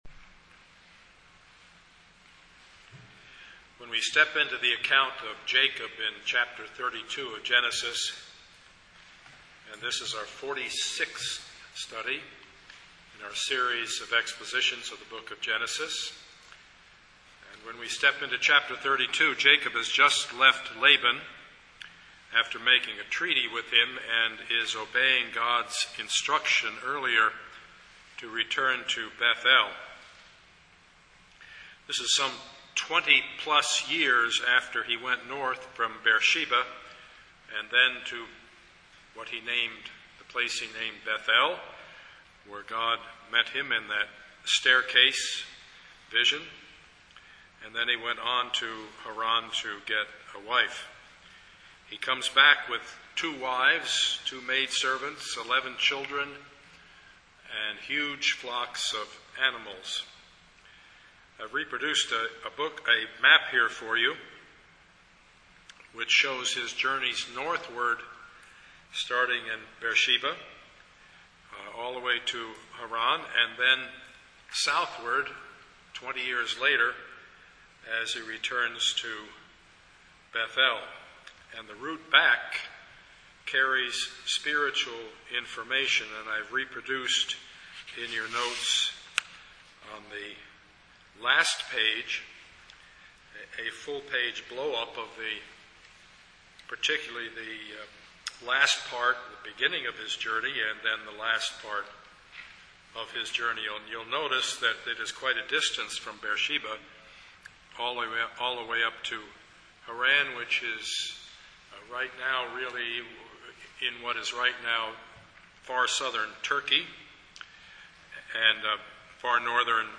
Passage: Genesis 32:7-32 Service Type: Sunday morning
Sermon